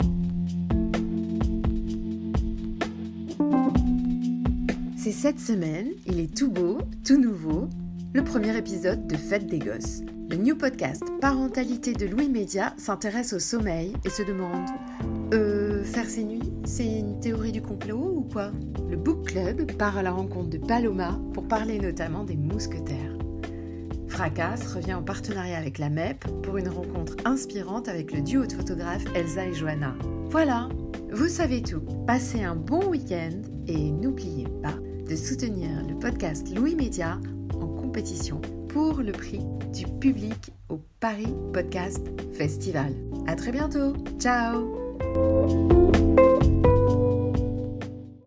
Voix off
20 - 45 ans - Soprano